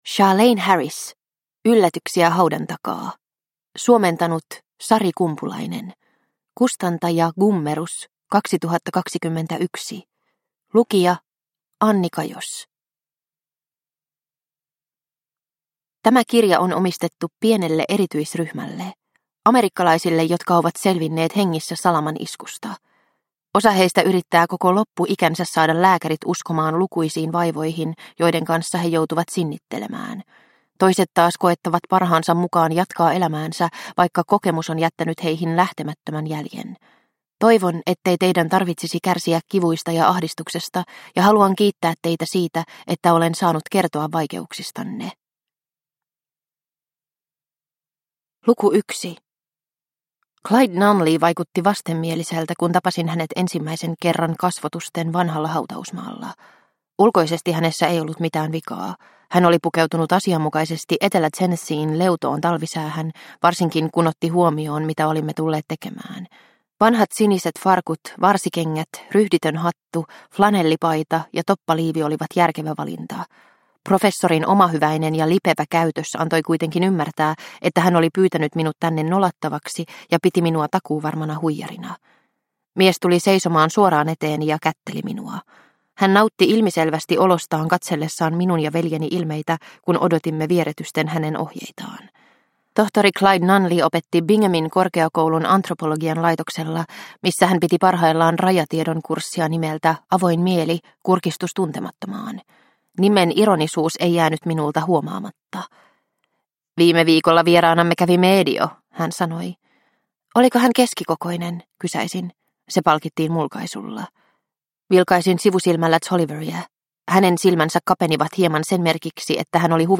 Yllätyksiä haudan takaa – Ljudbok – Laddas ner